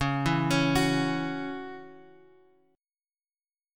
C#mbb5 chord